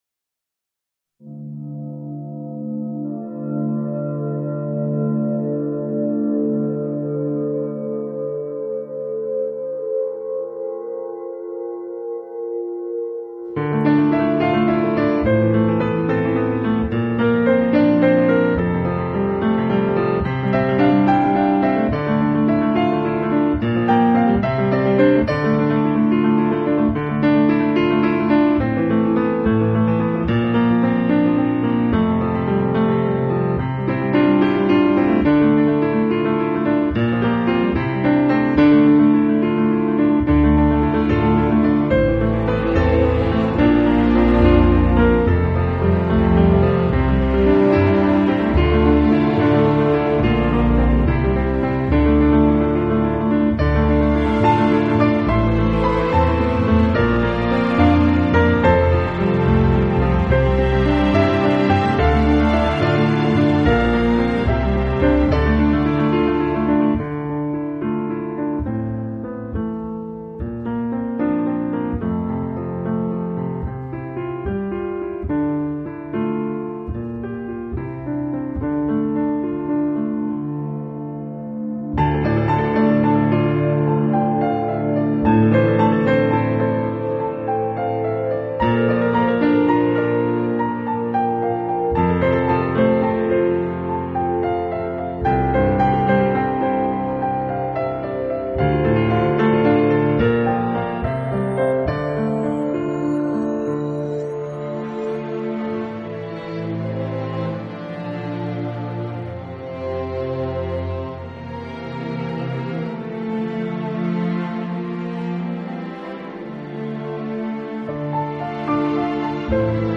【新世纪钢琴】
风格类型：New Age/Classical